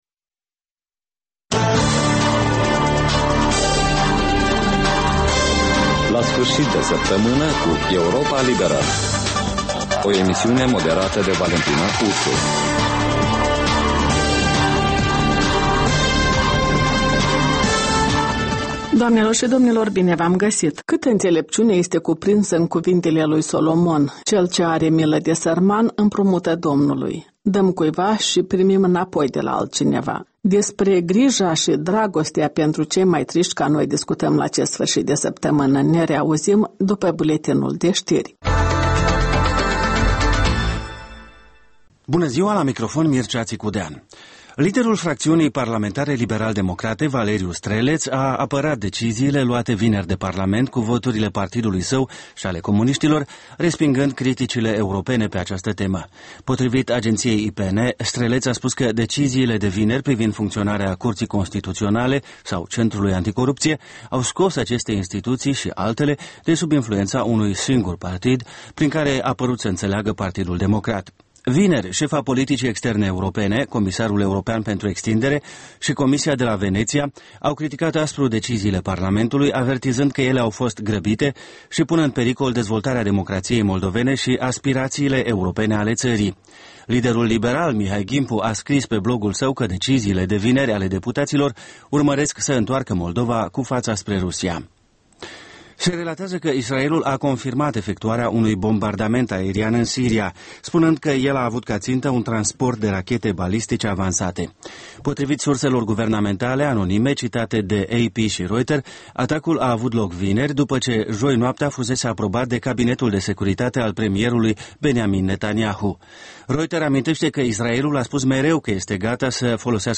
reportaje, interviuri, voci din ţară despre una din temele de actualitate ale săptămînii. In fiecare sîmbătă, un invitat al Europei Libere semneaza „Jurnalul săptămînal”.